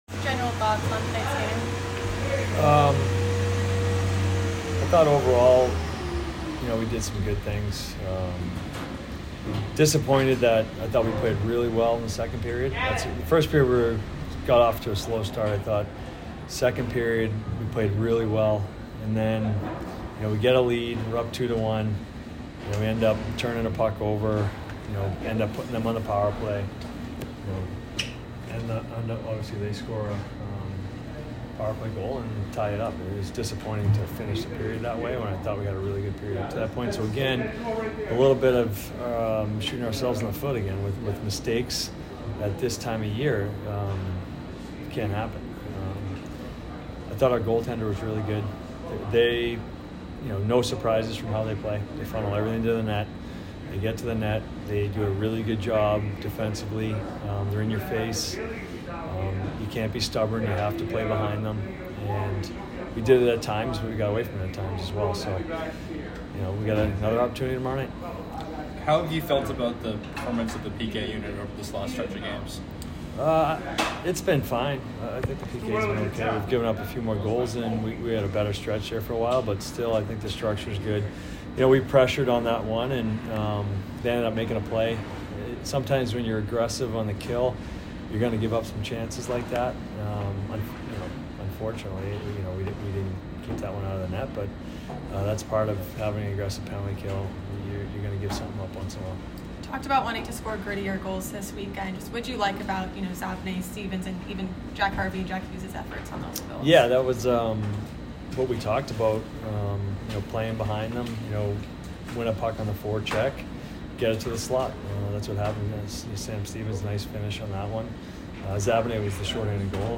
Men's Hockey / Providence Postgame Interview